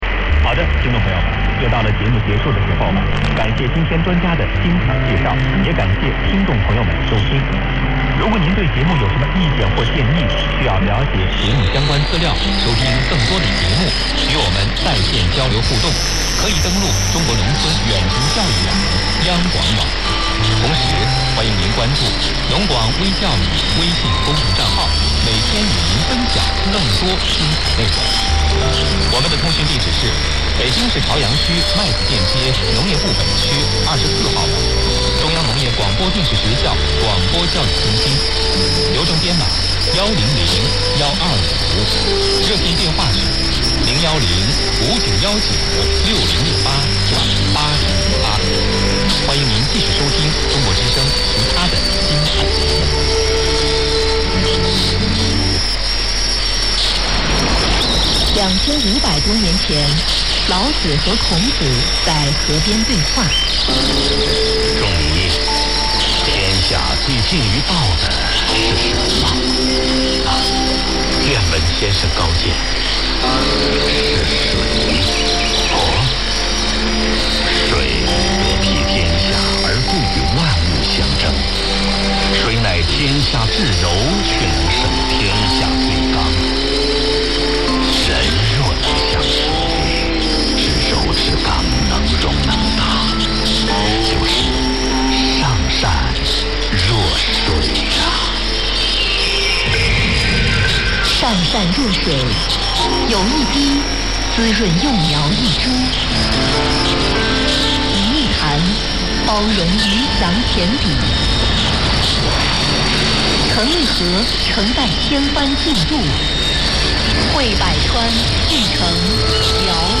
Nehéz tesztet csinálnom, mert nincs referenciám...majd megpróbálom...sajnos nálam nagyon zajos a környezet...a kínai adásnál kb. 2 perc kellett volna az egész óráig, de addigra kifédelt, de szerencsére elindítottam a felvételt már korábban...